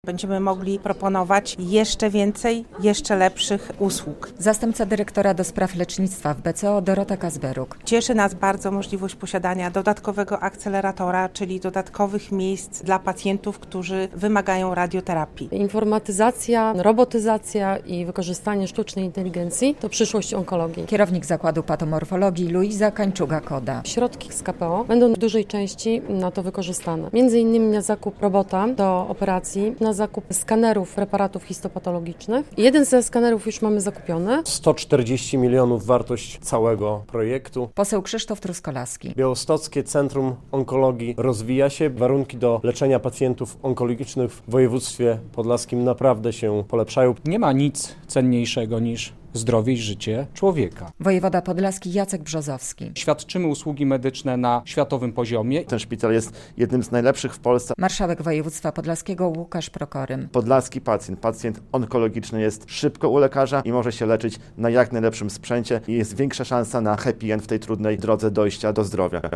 BCO z rekordowym dofinansowaniem na sprzęt i infrastrukturę - relacja